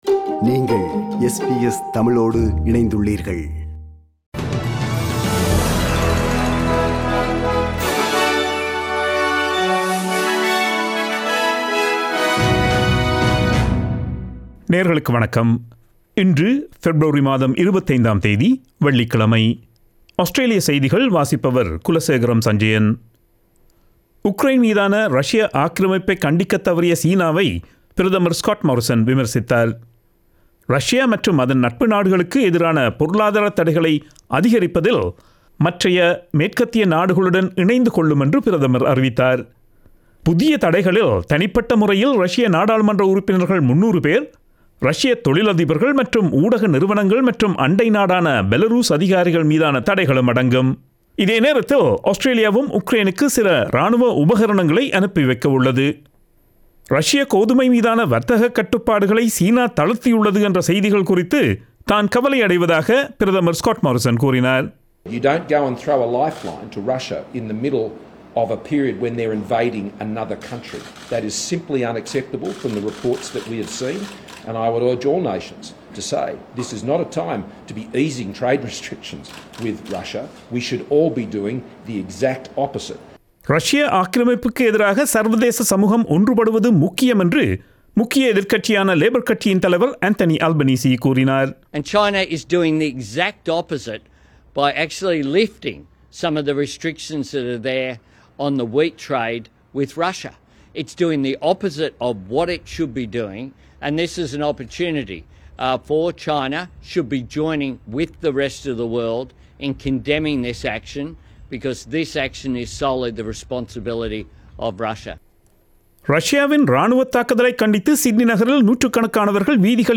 Australian news bulletin for Friday 25 February 2022.